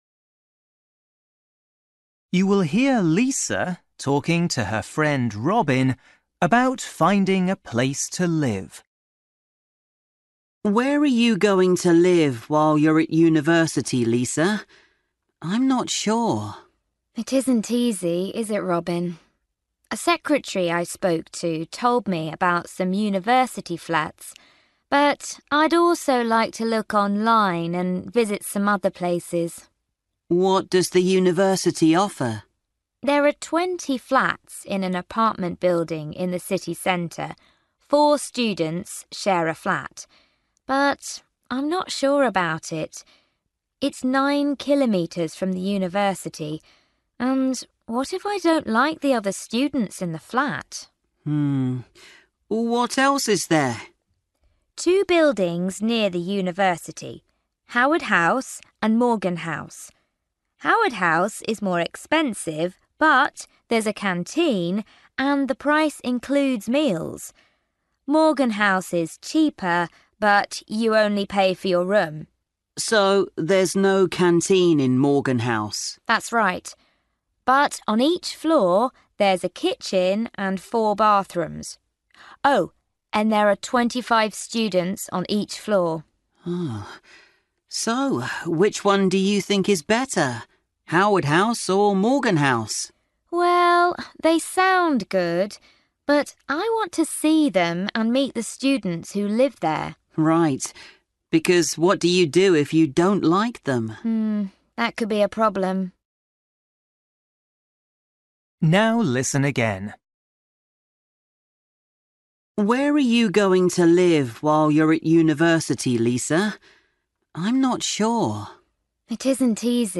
Bài tập trắc nghiệm luyện nghe tiếng Anh trình độ sơ trung cấp – Nghe một cuộc trò chuyện dài phần 12